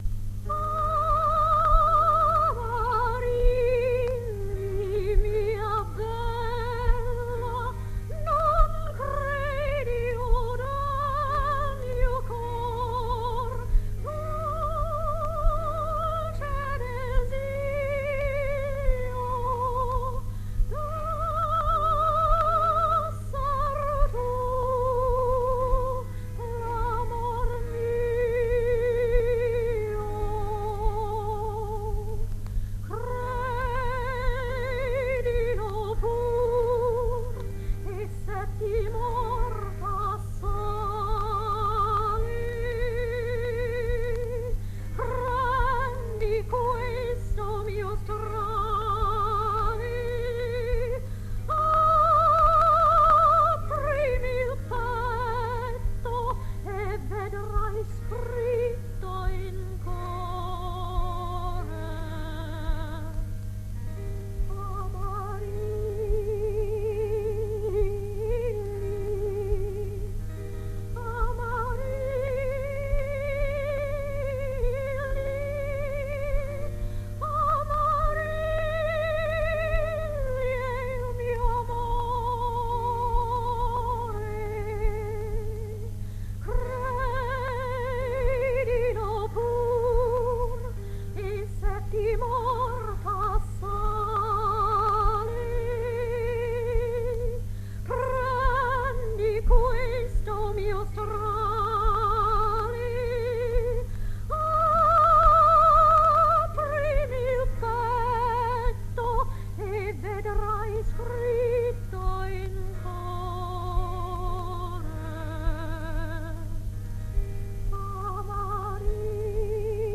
Caccini, Amarilli (madrigale da Le nuove musiche).mp3